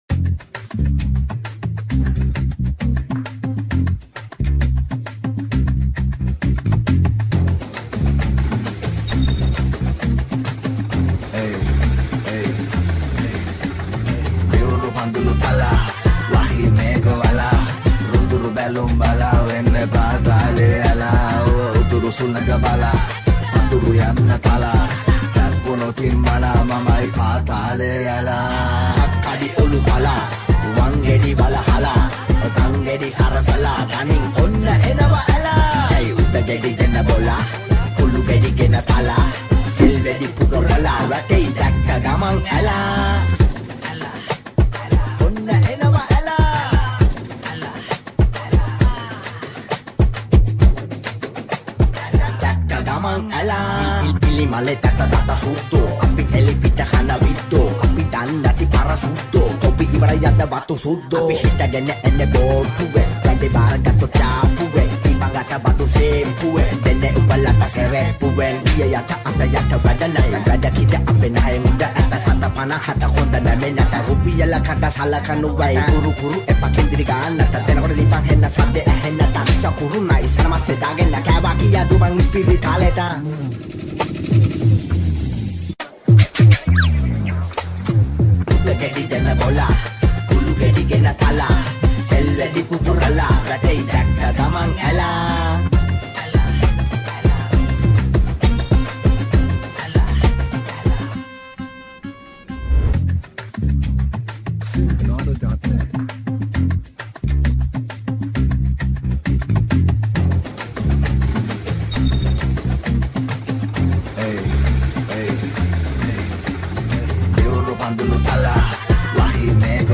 Category: Rap Songs